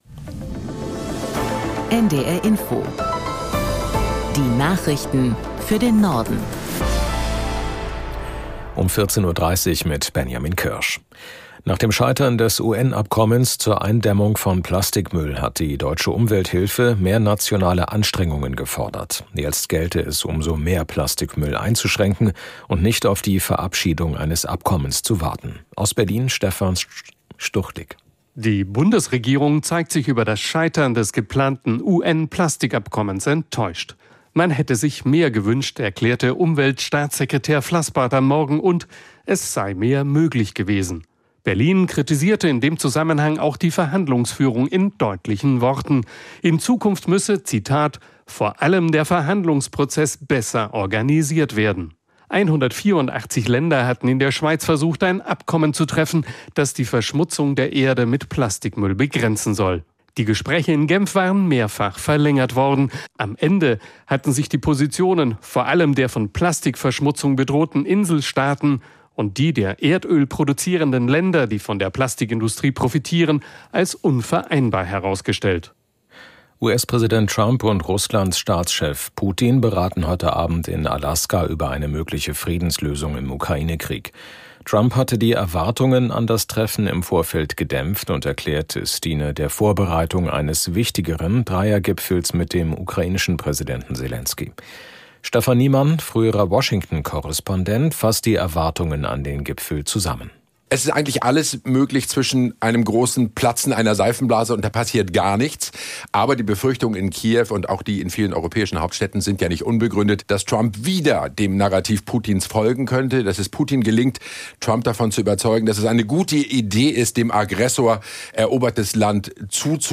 1 Nachrichten 5:54 Play Pause 19m ago 5:54 Play Pause बाद में चलाएं बाद में चलाएं सूचियाँ पसंद पसंद 5:54 Die aktuellen Meldungen aus der NDR Info Nachrichtenredaktion.